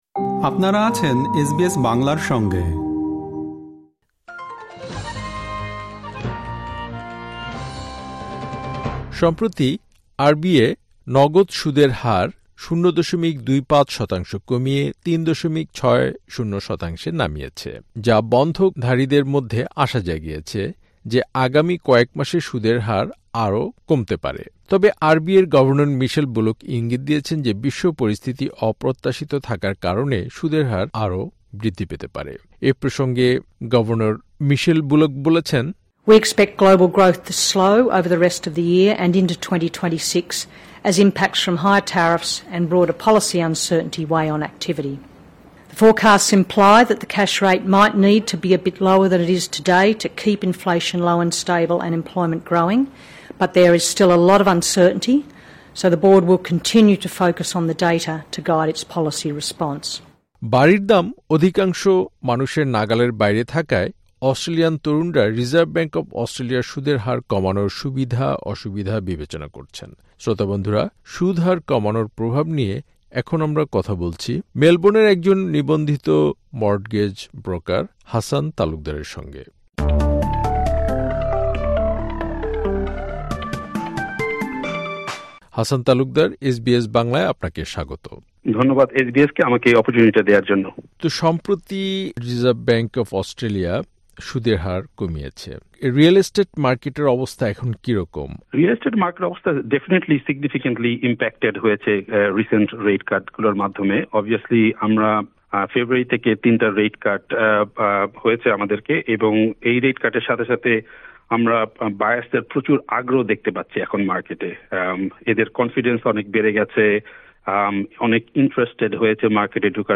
এ সপ্তাহের খবর: ২২ আগস্ট, ২০২৫